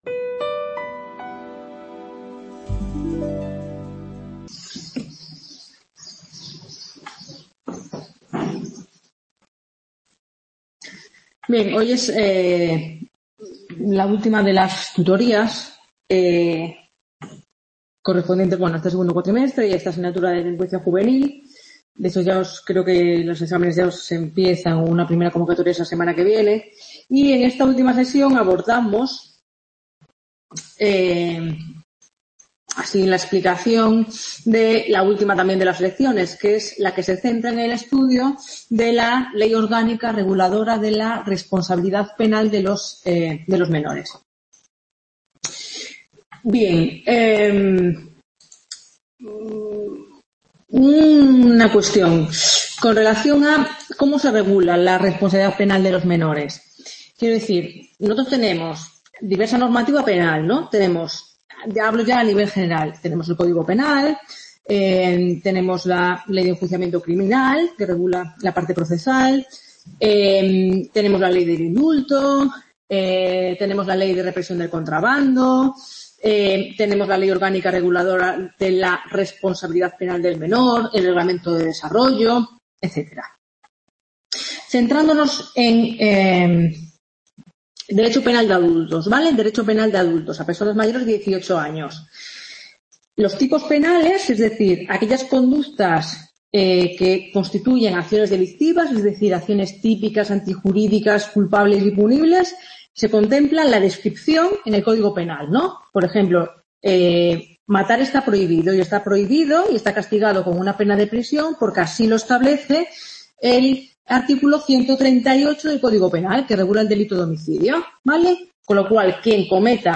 Lección 10